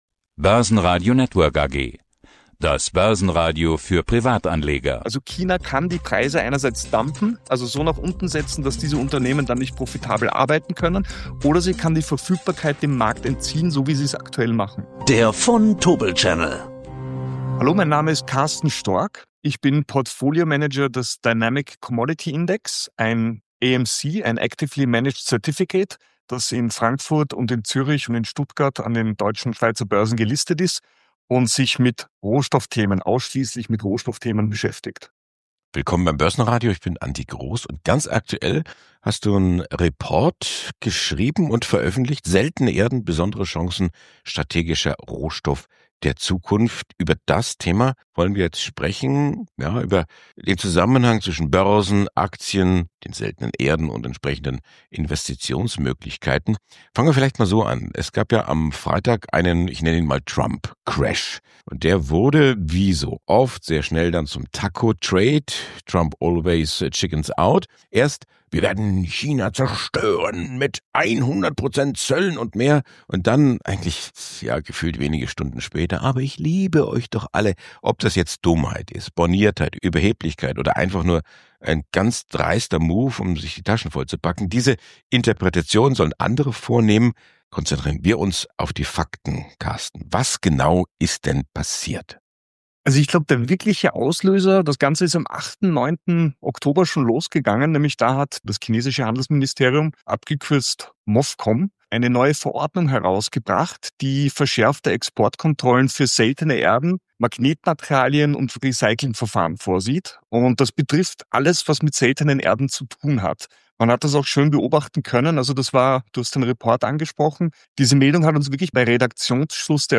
Für Anleger interessant: der neue Index-Tracker von Vontobel. Ein Gespräch über Chancen, Risiken und geopolitische Macht.